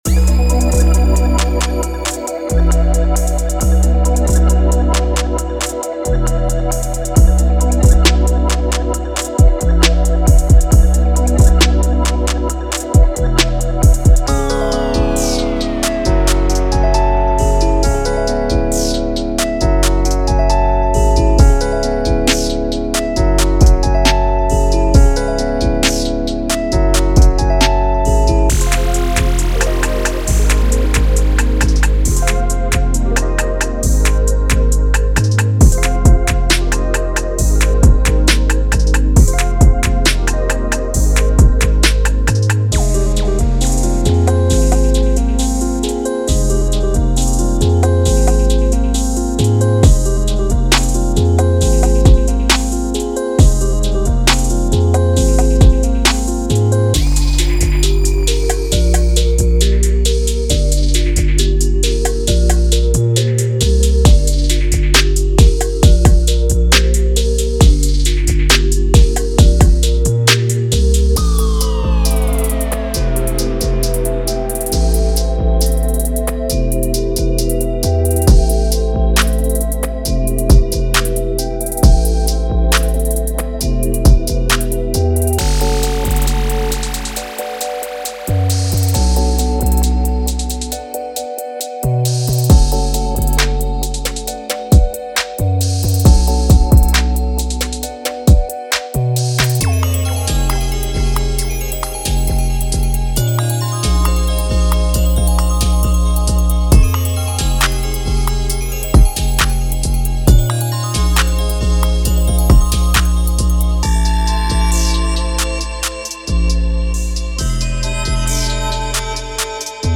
Featuring 88 top-quality loops, including synth, bass, drums, vocal, and FX loops, this collection offers everything you need to create mesmerizing music effortlessly.
Synth Loops: Immerse your listeners in a sea of lush, ambient synths that set the perfect mood.
Bass Loops: Lay down solid foundations with deep, resonant basslines that bring power and groove to your beats.
From crisp snares and booming kicks to intricate hi-hats, these loops offer the perfect rhythm section for your productions.
Vocal Loops: Add a unique, human touch with our selection of ethereal vocal loops.
From dramatic risers to impactful drops, these effects will help you craft seamless transitions and highlight key moments.